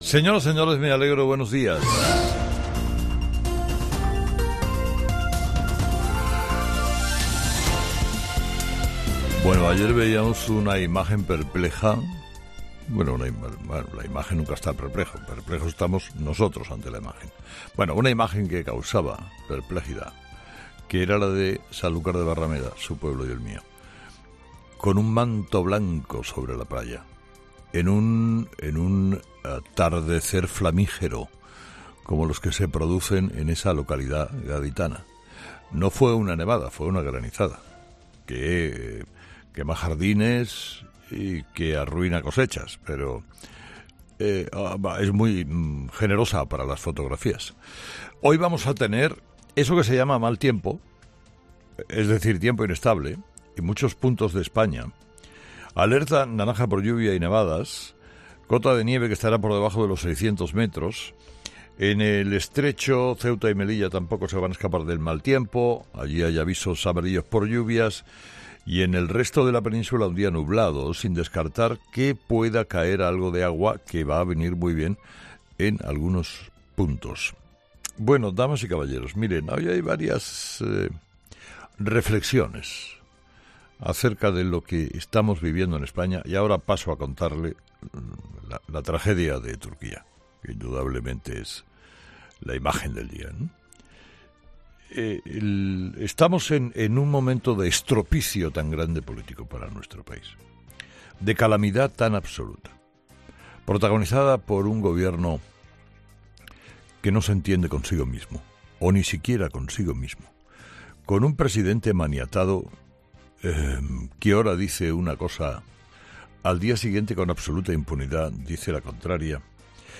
Carlos Herrera, director y presentador de 'Herrera en COPE', comienza el programa de este miércoles analizando las principales claves de la jornada, que pasan, entre otros asuntos, por la tensión que se vive en el seno del PSOE tras los últimos acontecimientos relacionados con la ley del 'solo sí es sí'.